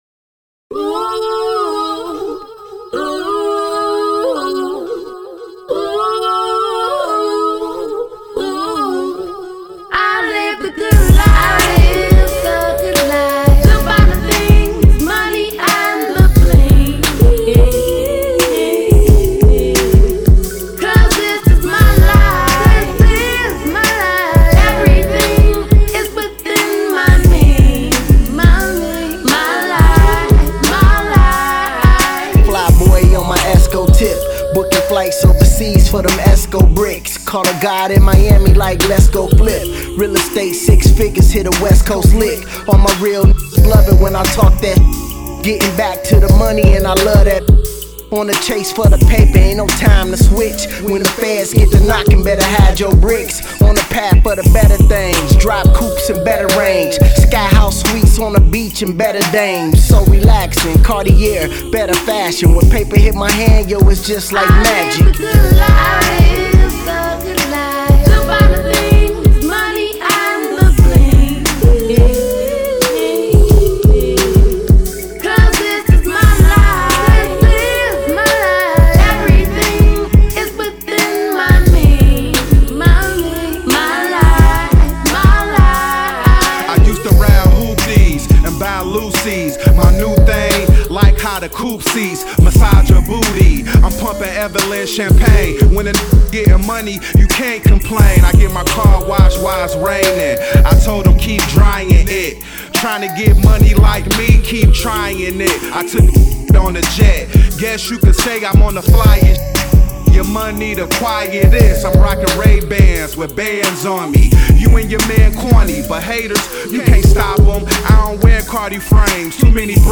Hiphop
timeless hiphop classic